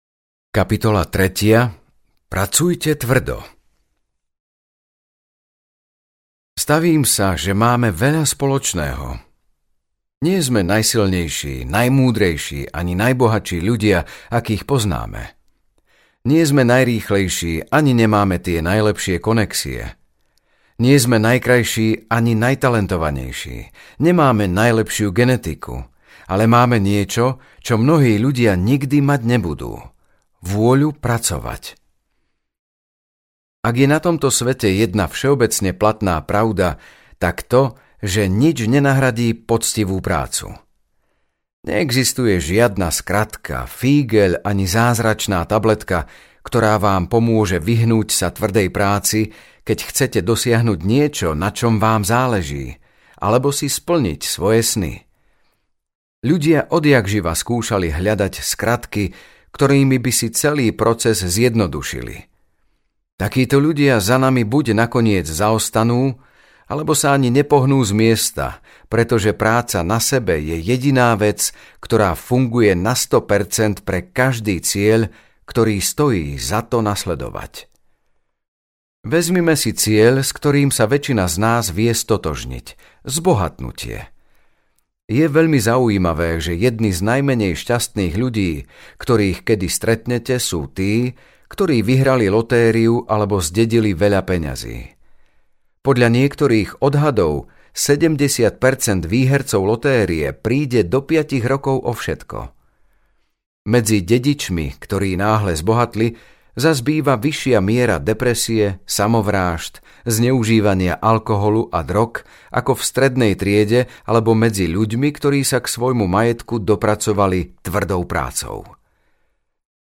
Buďte užitoční audiokniha
Ukázka z knihy
budte-uzitocni-audiokniha